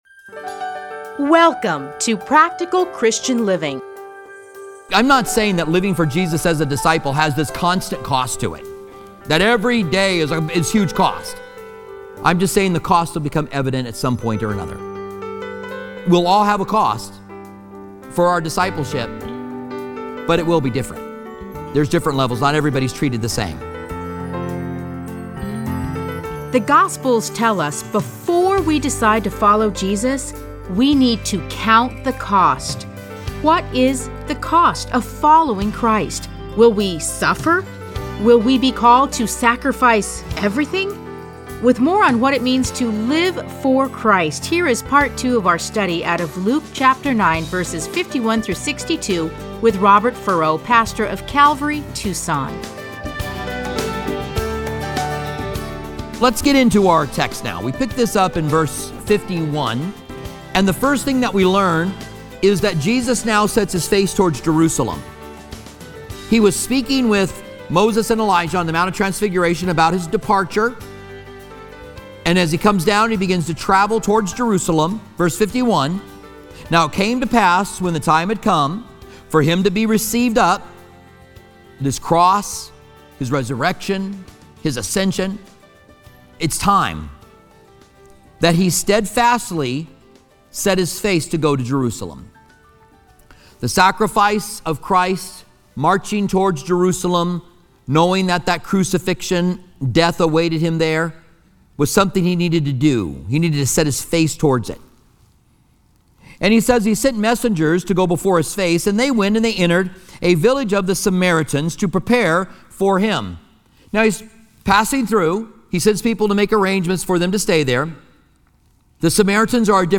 Listen to a teaching from Luke Luke 9:51-62.